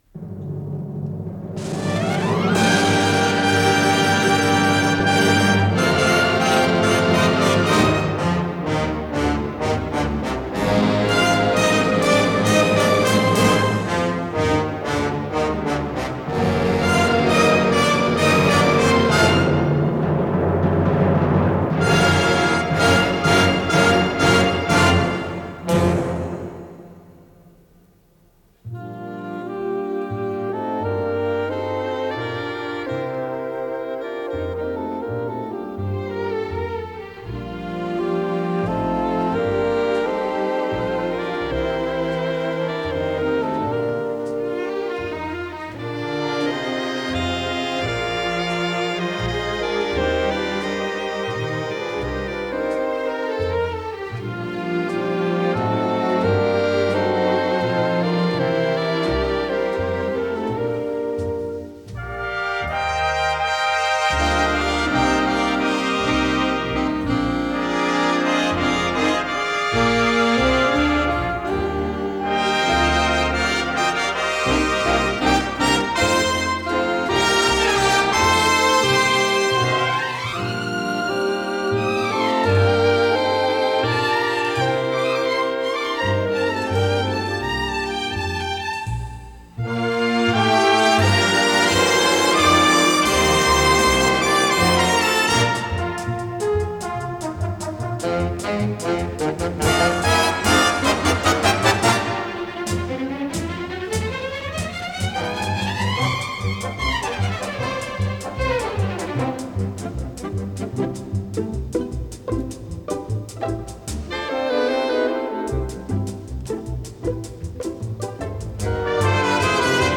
с профессиональной магнитной ленты
ПодзаголовокДля эстрадного оркестра, си бемоль мажор
ВариантДубль моно